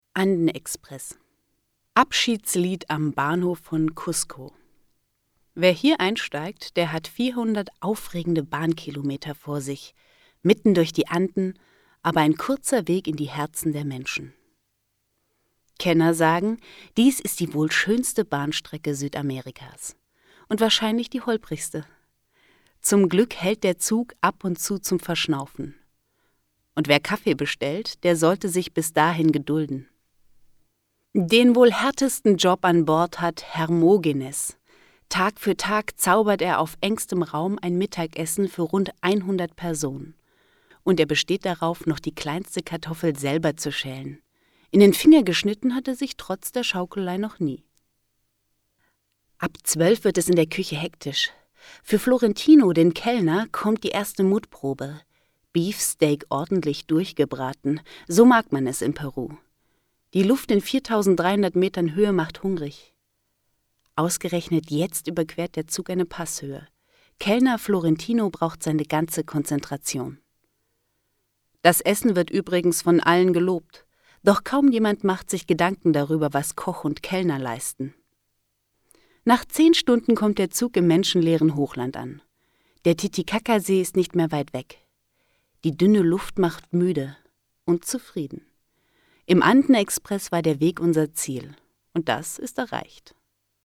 Neue junge Stimme, gut für Hörspiele und Computerspiele geeignet.
Sprechprobe: eLearning (Muttersprache):